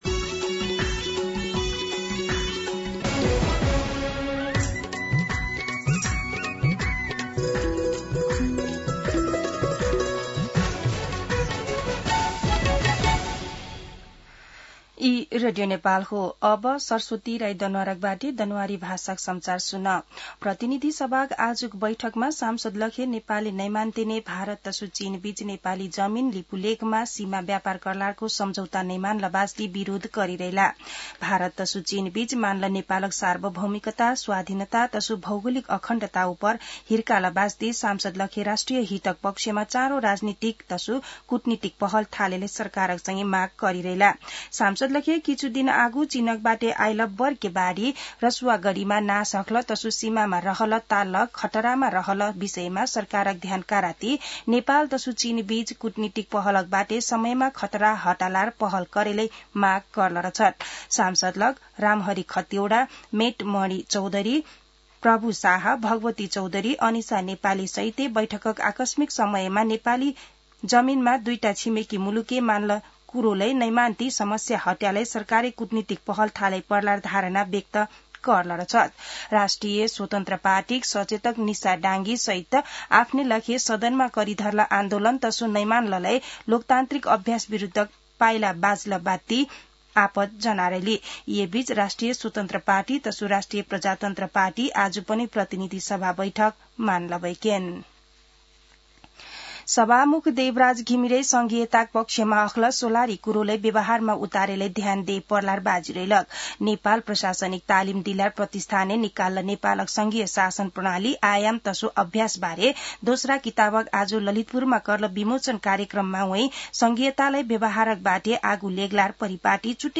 दनुवार भाषामा समाचार : ६ भदौ , २०८२
Danuwar-News-1-2.mp3